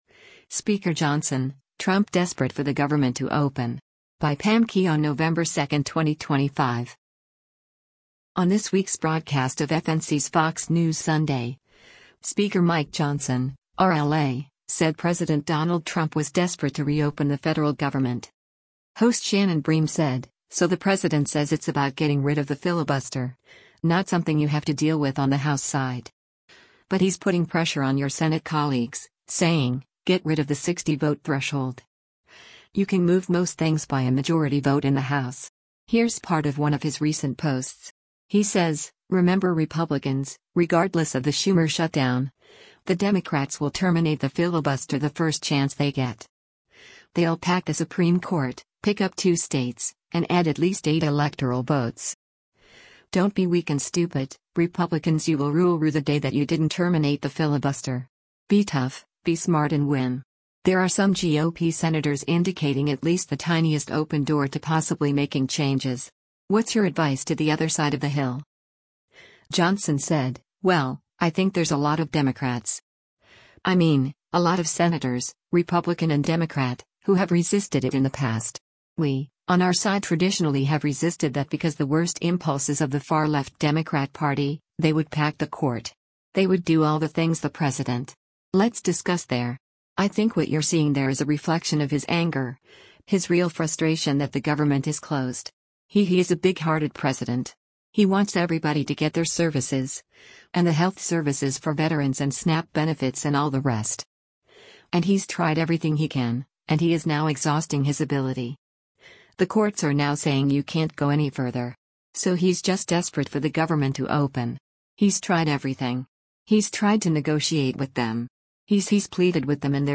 On this week’s broadcast of FNC’s “Fox News Sunday,” Speaker Mike Johnson (R-LA) said President Donald  Trump was “desperate” to reopen the federal government.